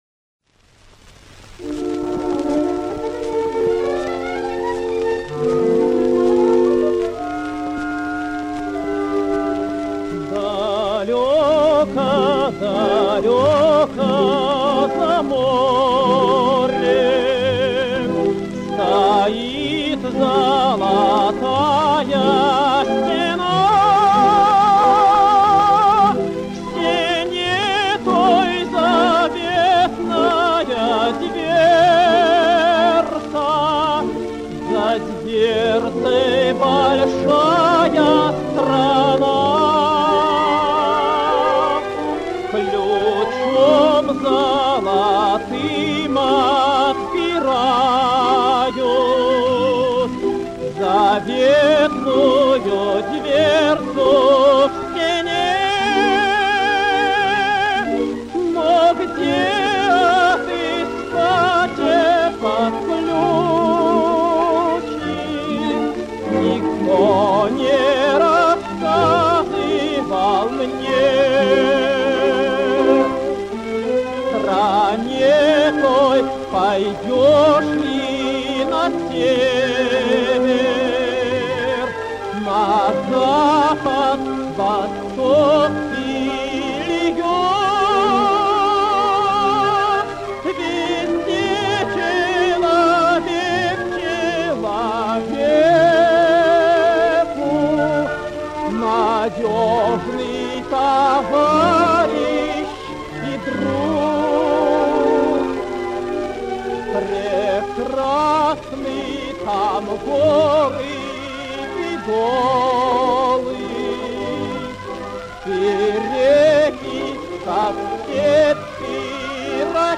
Певческий голос лирический тенор